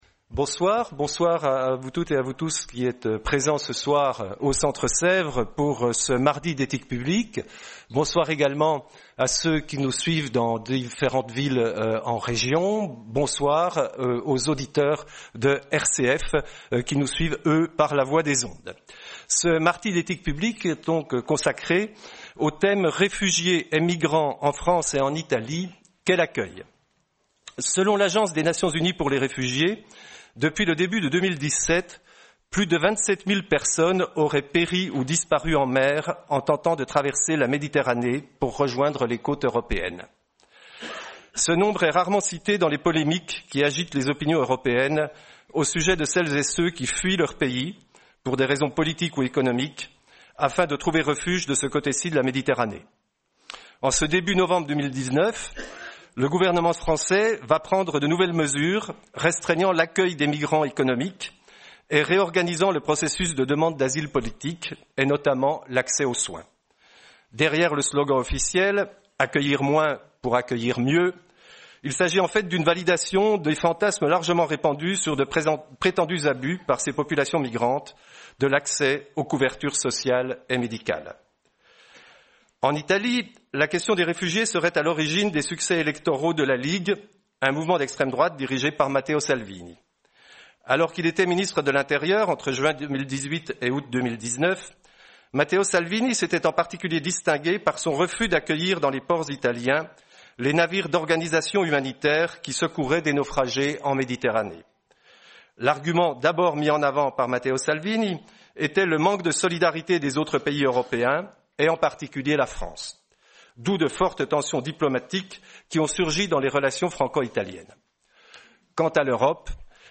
Soirée Mardi d’éthique publique du 5 novembre 2019.